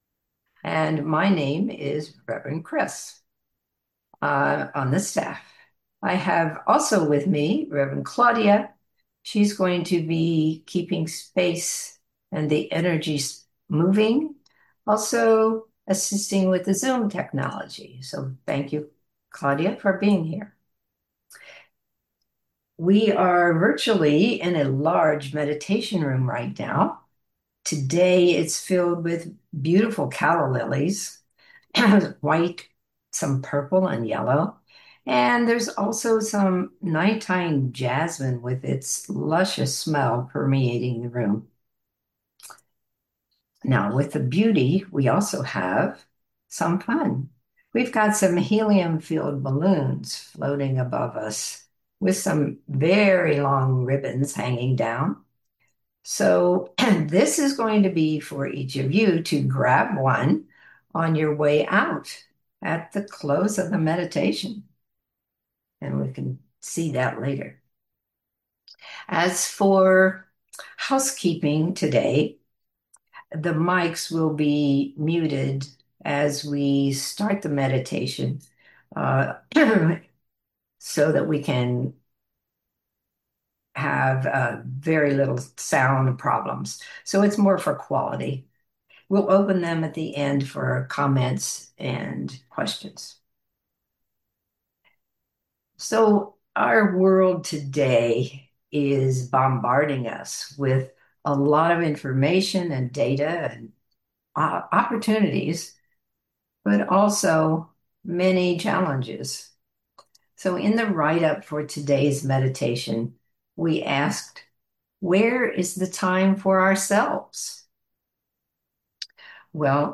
33 minutes Recorded Live on July 24